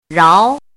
怎么读
ráo náo
rao2.mp3